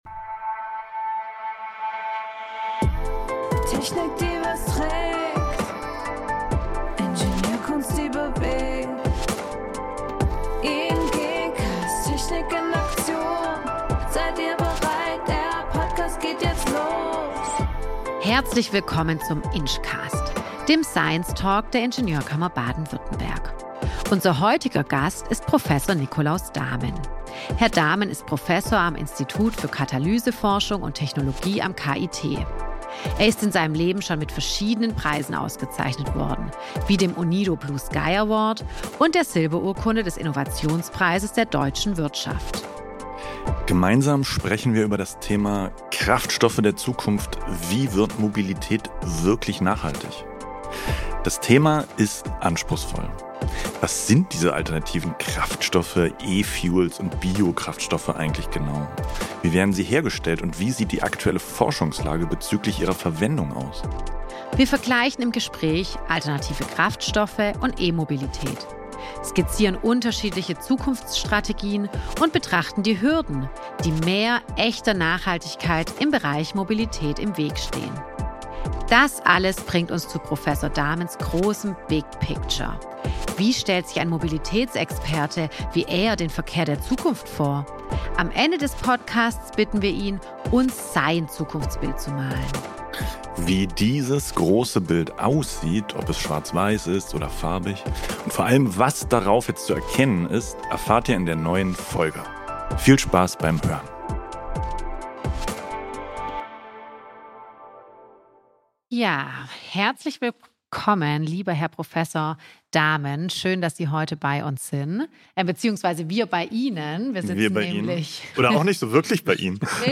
Was sind diese alternativen Kraftstoffe, E-Fuels und Biokraftstoffe eigentlich genau? Wie werden sie hergestellt und wie sieht die aktuelle Forschungslage bzgl. ihrer Verwendung aus? Wir vergleichen im Gespräch alternative Kraftstoffe und E-Mobilität, skizzieren unterschiedliche Zukunftsstrategien und betrachten die Hürden, die mehr echter Nachhaltigkeit im Bereich Mobilität im Weg stehen.